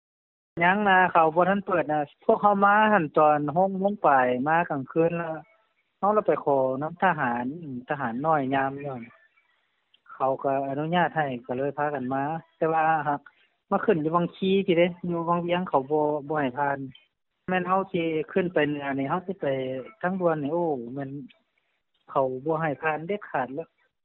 ສຽງຂອງນັກທ່ອງທ່ຽວທີ່ເດີນທາງມາຈາກວັງວຽງເວົ້າກ່ຽວກັບທາງດ່ວນ